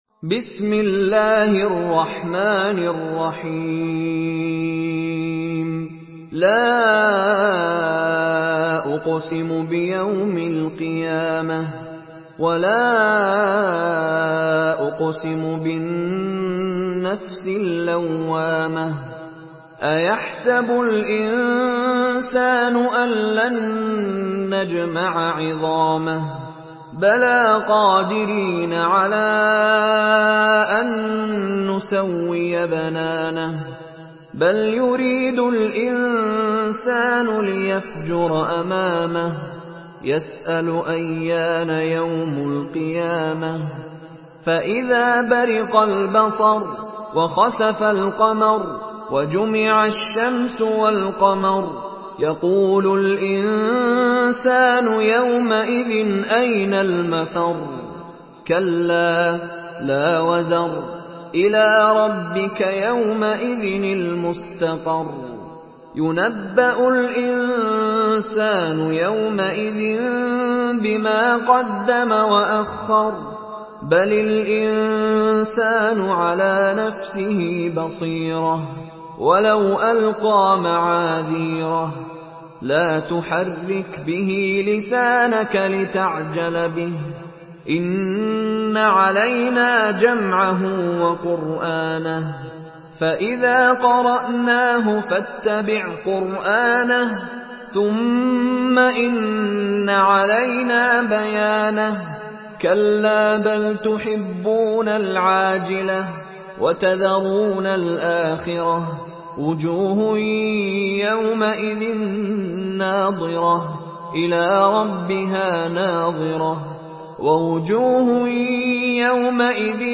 Surah القيامه MP3 by مشاري راشد العفاسي in حفص عن عاصم narration.